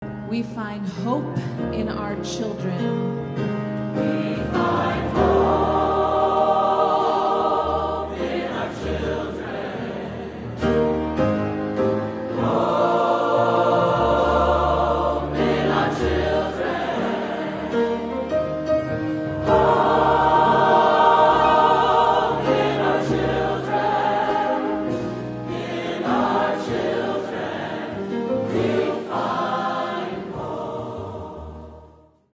SATB
Accompaniment